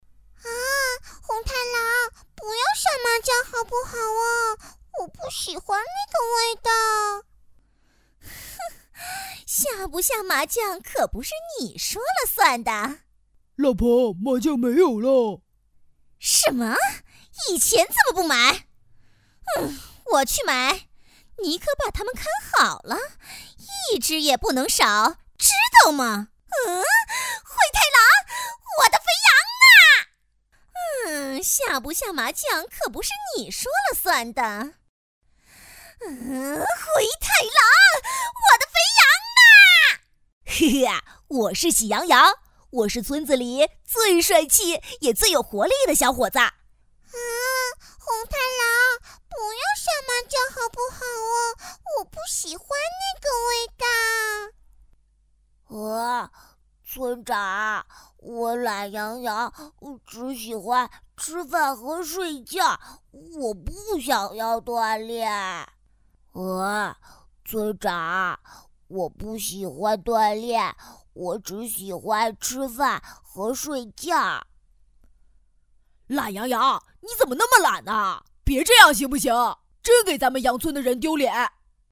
女131号童声模仿